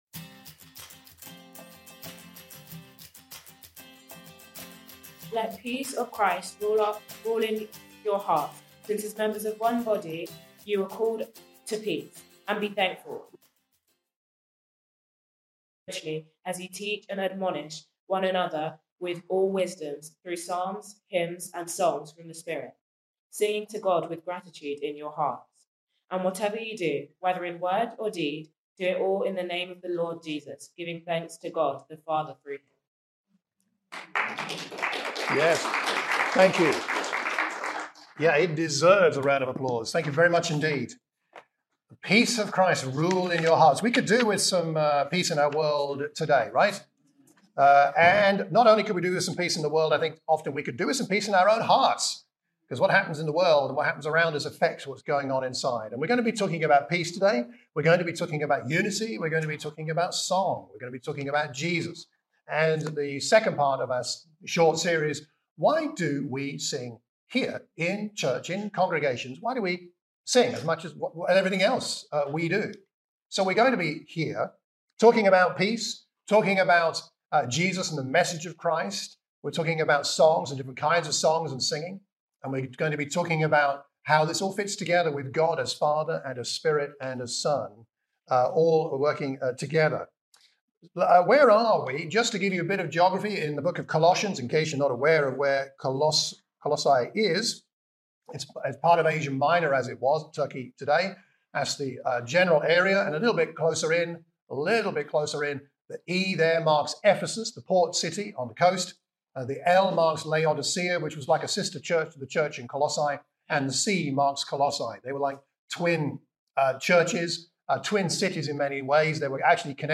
In this second sermon in a series about why we sing when we are gathered on a Sunday, we look at the passage in Colossians 3.